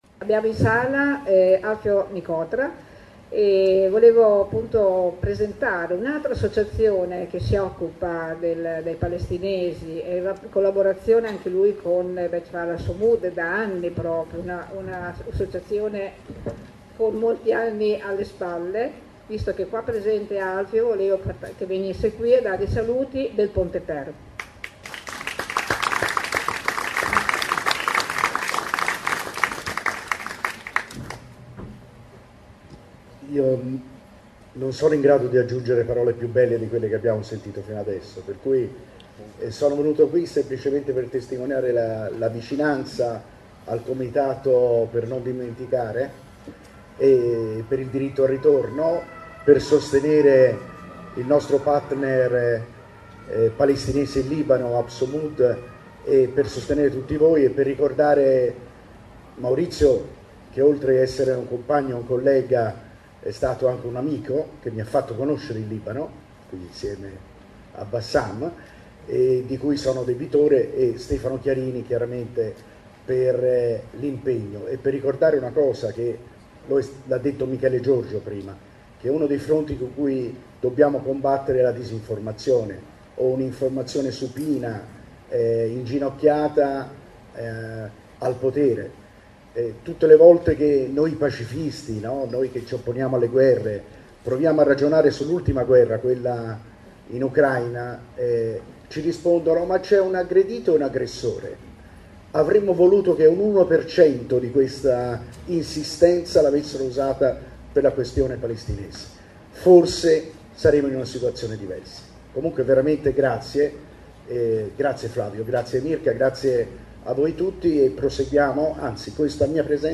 La cerimonia si è svolta sabato 15 aprile alle ore 10.30 presso il municipio di Modena (sala di rappresentanza).